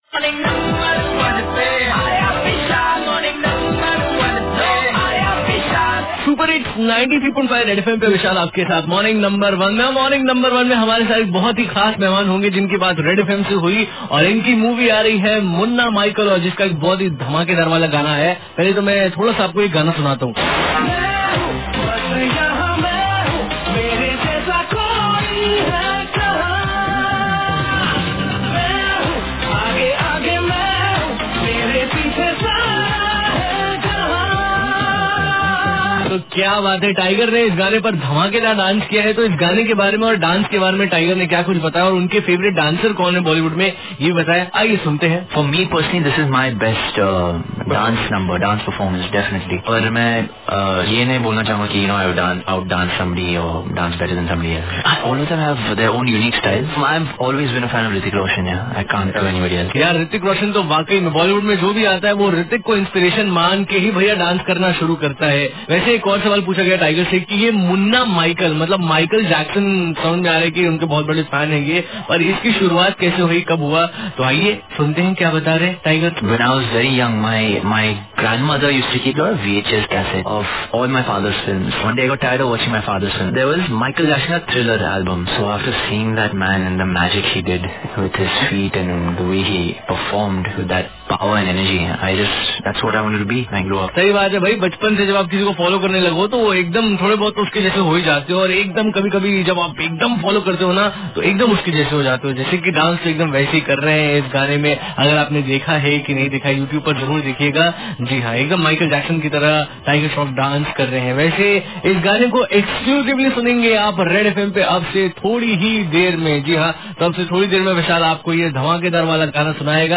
TIGER SHROFF INTERVIEW FOR MUNNA MICHAEL